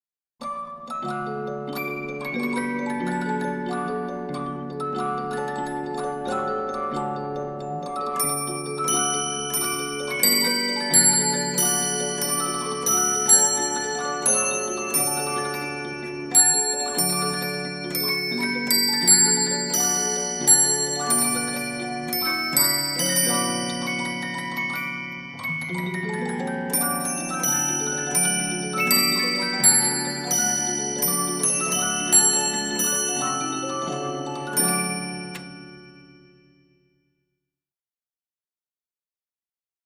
Boîte à musique